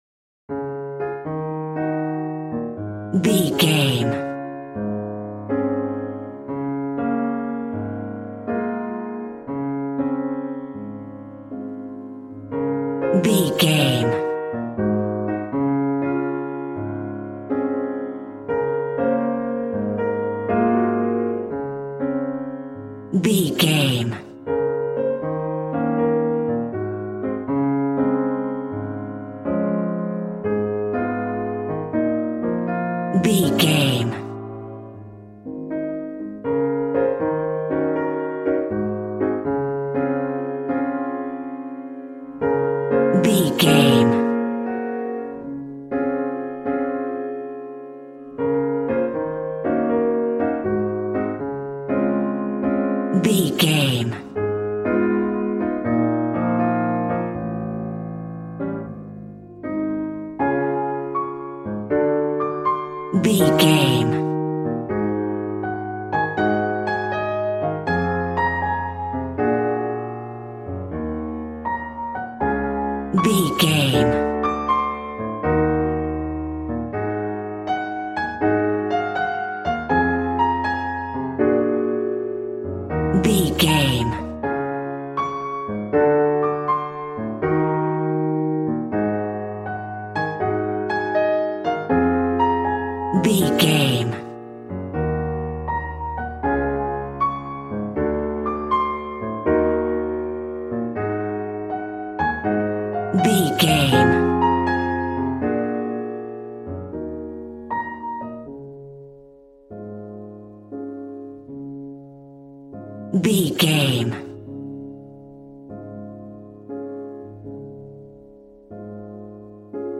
Smooth jazz piano mixed with jazz bass and cool jazz drums.,
Ionian/Major
A♭